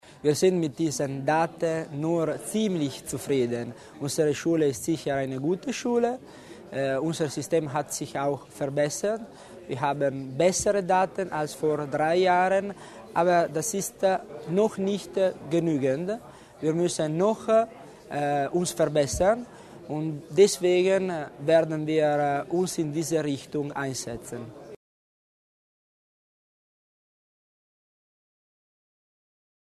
Landesrat Tommasini zu den Ergebnissen in den italienischen Schulen